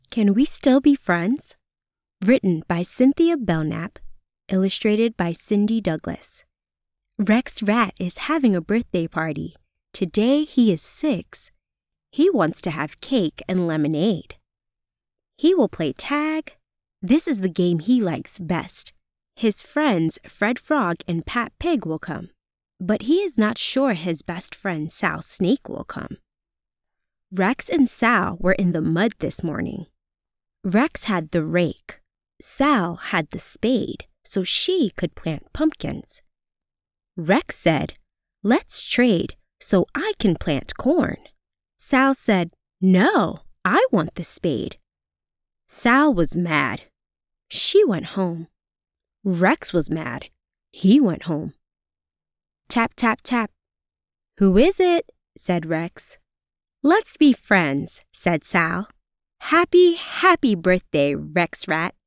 Kids Narration
My voice is cheerful, youthful. bright, distinctive and versatile.